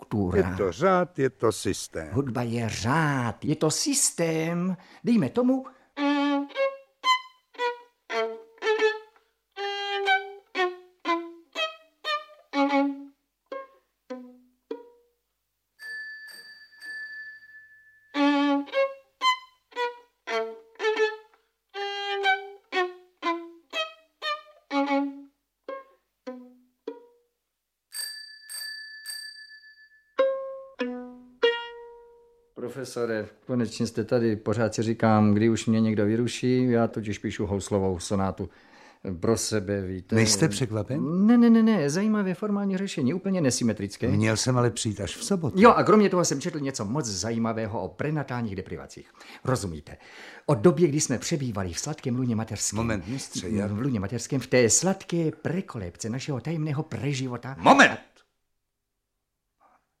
Audiobook
Audiobooks » Short Stories